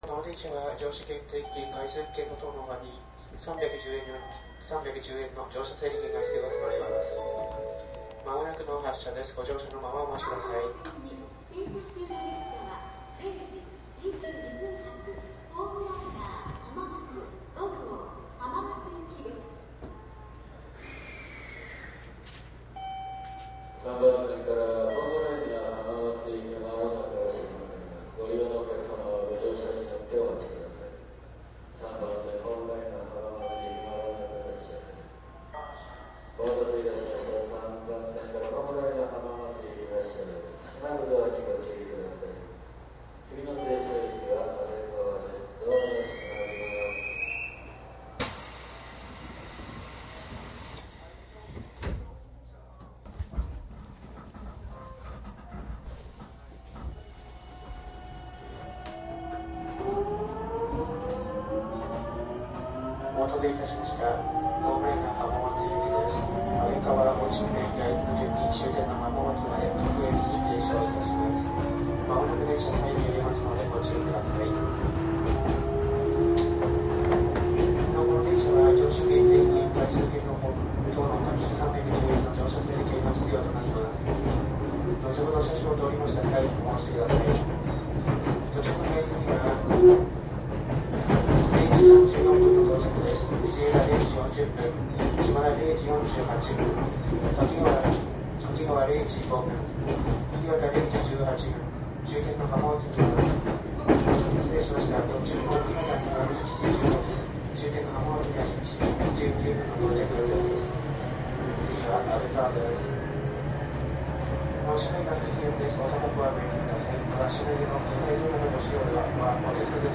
東海道線373系 ホームライナー浜松5号 走行音 ＣＤ♪
JR東海道線ホームライナー（373系）を収録。
■【ホームライナー】静岡→浜松 クモハ373－2＜DATE04-1-17＞
DATかMDの通常SPモードで録音（マイクＥＣＭ959）で、これを編集ソフトでＣＤに焼いたものです。